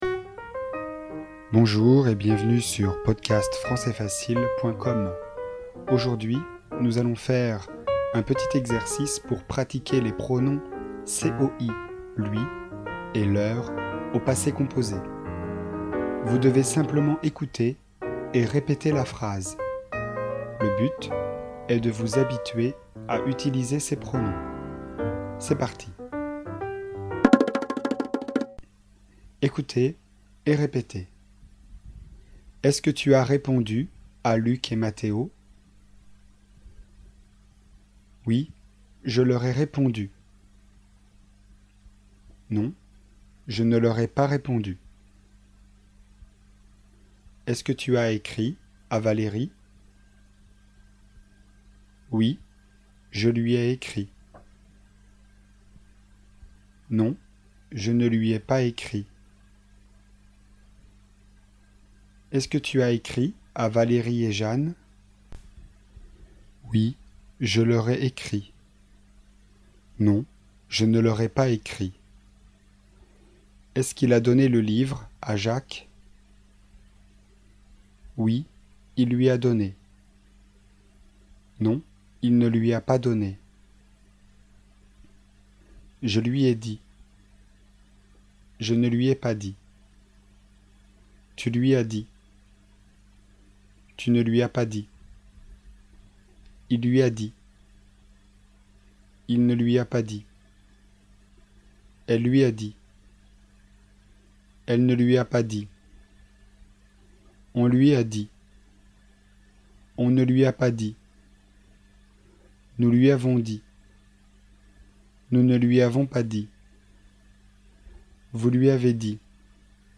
Leçon de grammaire, niveau intermédiaire (A2), sur le thème des pronoms.
Écoutez et répétez les phrases.